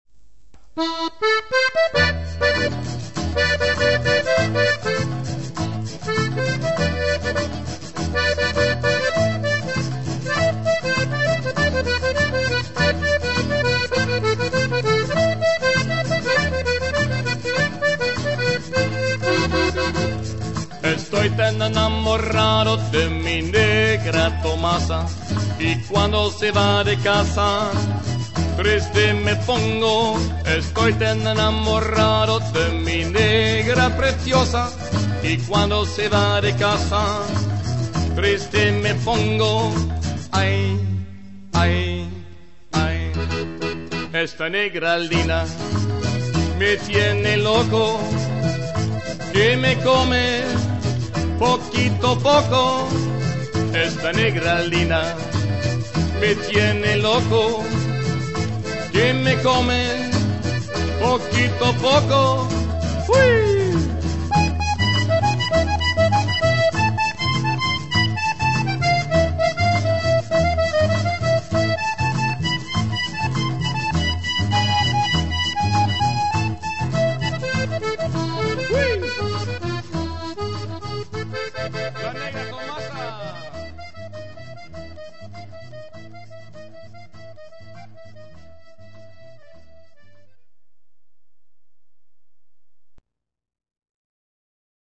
Internationaal repertoire